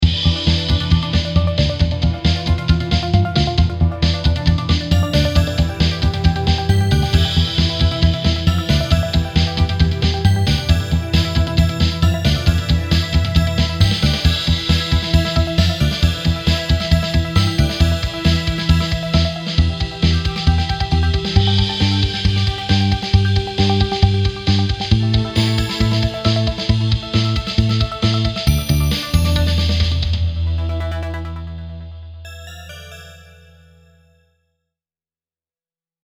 Inspired by the Flash, I decided to write a very simple bass line that is reminiscent of some of the more popular superhero film scores popular in the theaters.
After writing the initial bassline, I added in some extra software patches to add a touch of sci-fi and an “epic” feel to the tune. Being a song challenge, this is a rough mix, but it gives you an idea about the possibilities.